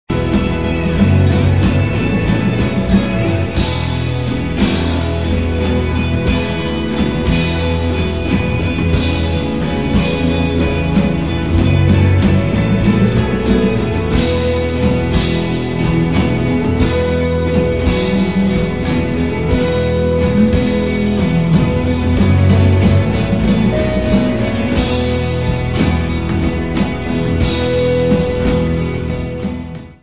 Woodwinds, tribal drums and an underlying ethereal sound
Loud, heartfelt and slightly wicked
tribal-ethereal-instrumental-rock-and-roll